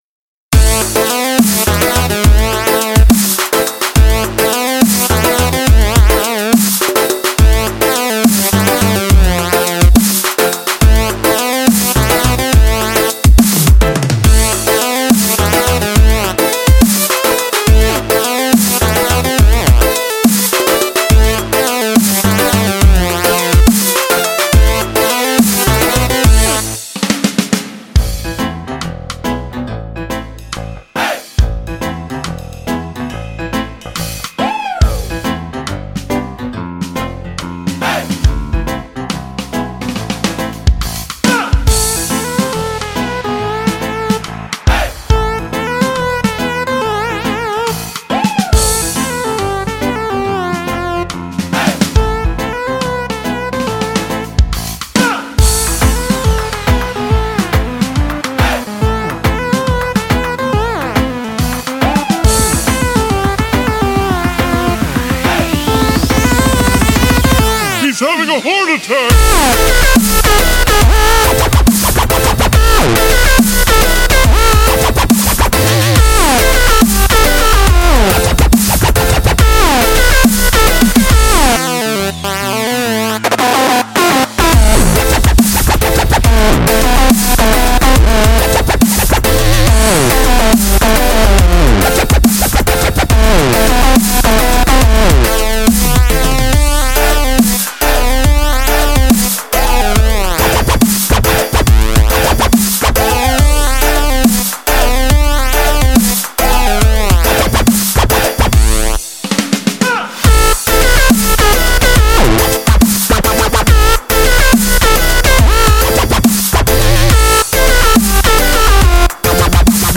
Žánr: Electro/Dance
Genres: Dance, Music, Electronic